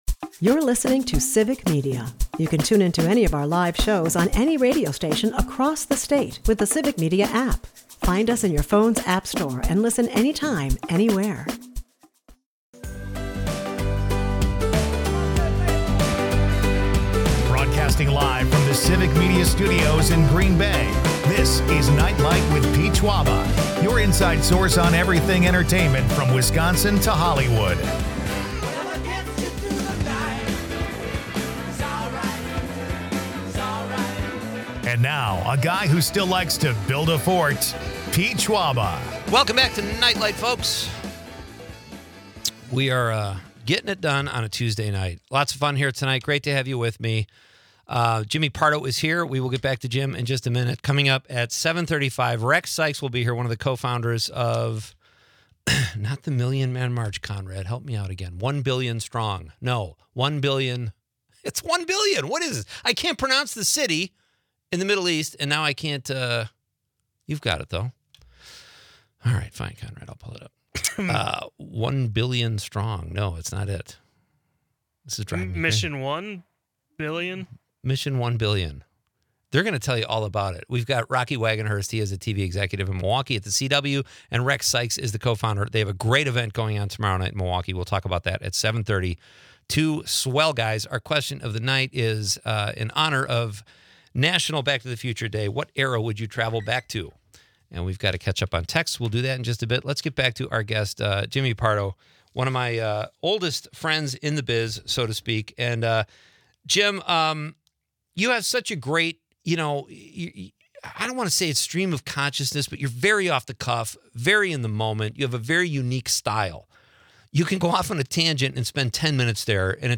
The show is peppered with listener interactions about which era they'd time travel to, ranging from the 1940s jazz scene to medieval times, all in celebration of National Back to the Future Day.